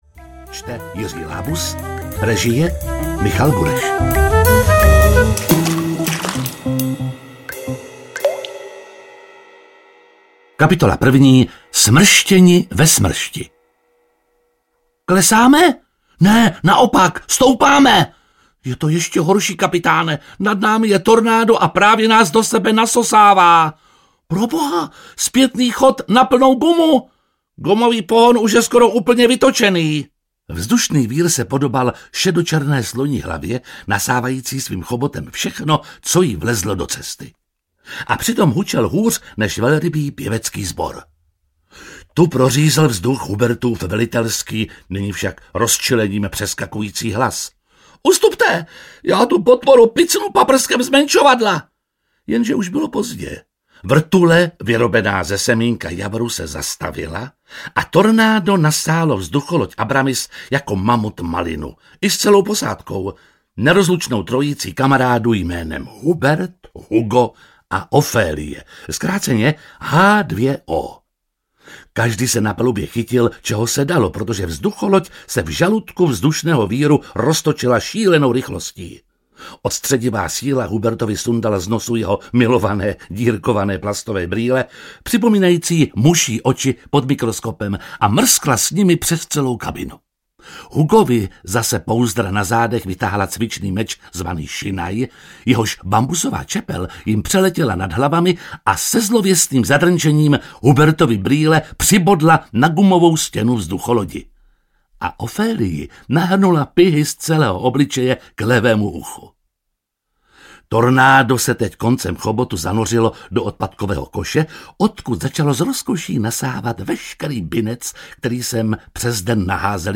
H2O a pastýřové snů audiokniha
Ukázka z knihy